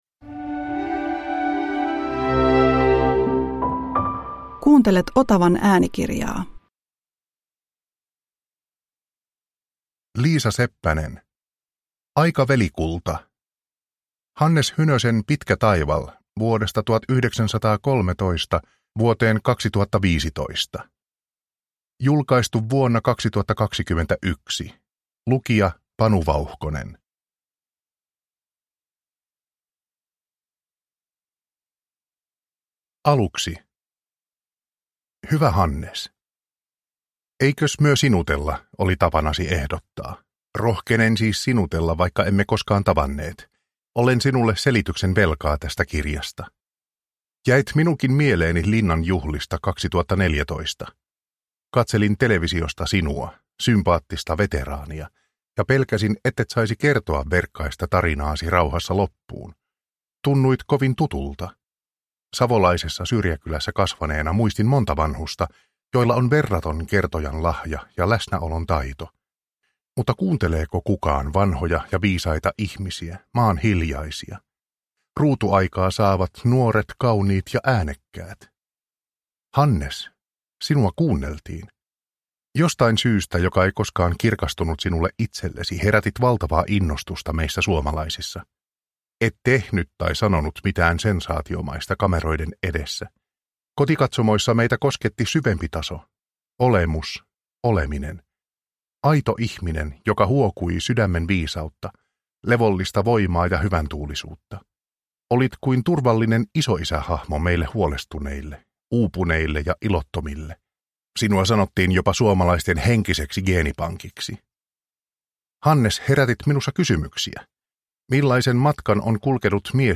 Aika velikulta – Ljudbok – Laddas ner